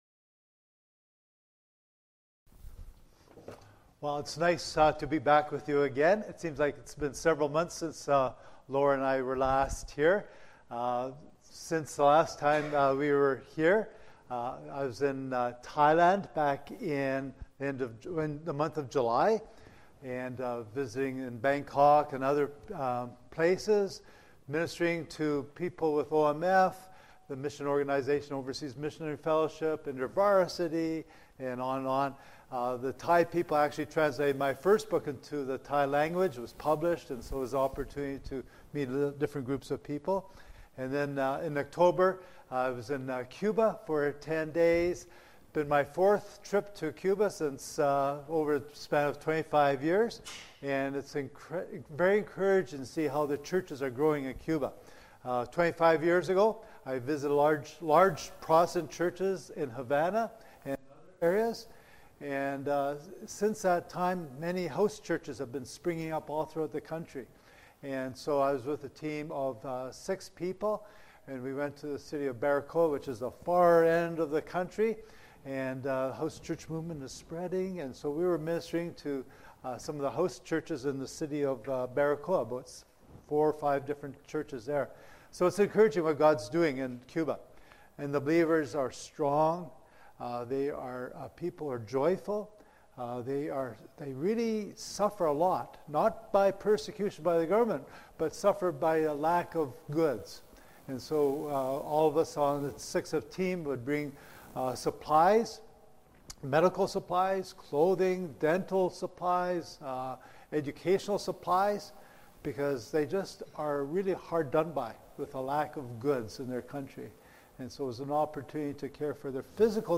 Passage: Psalm 105: 1-11 Service Type: Sunday Worship